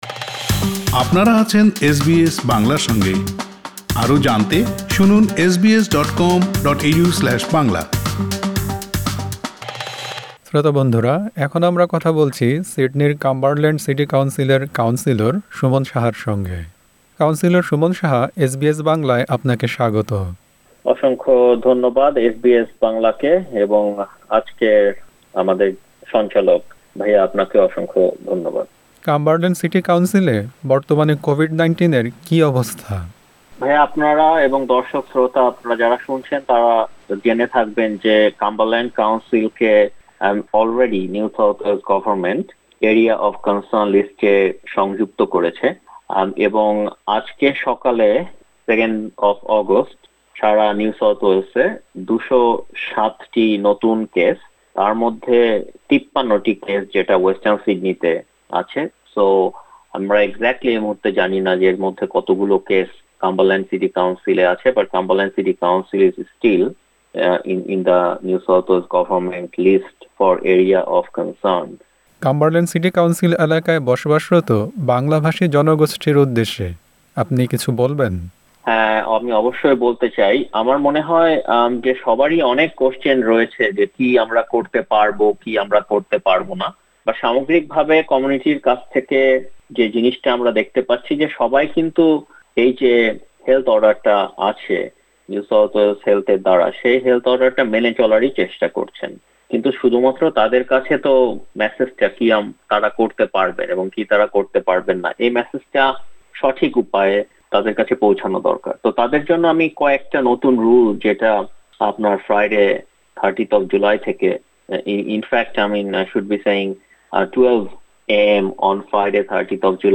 লকডাউন পরিস্থিতি নিয়ে এসবিএস বাংলার সঙ্গে কথা বলেছেন সিডনির কাম্বারল্যান্ড সিটি কাউন্সিলের বাংলাভাষী কাউন্সিলর সুমন সাহা।